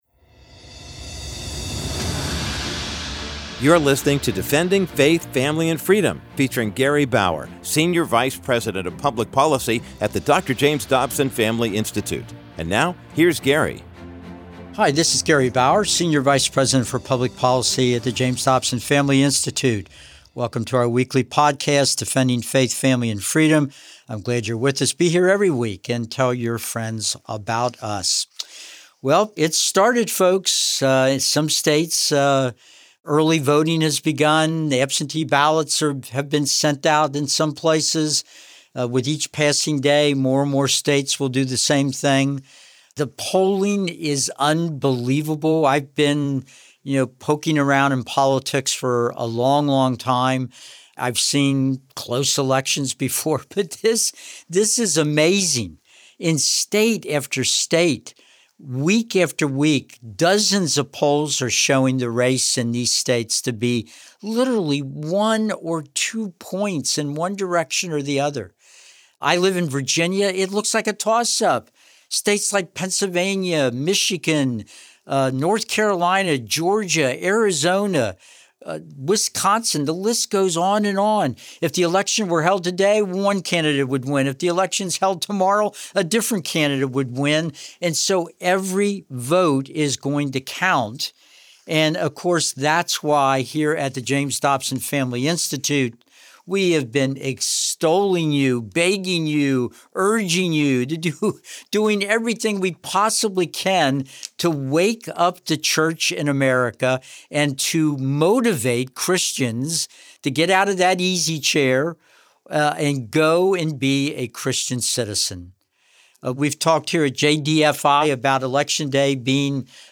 In this week's episode, Gary Bauer discusses the history of Supreme Court decisions, dating from the 1960s until today, that have significantly weakened religious freedoms. Bauer plays a recent speech given by former President Donald Trump in Pennsylvania, where he pleaded with Christians and gun owners to vote for their God-given inalienable rights.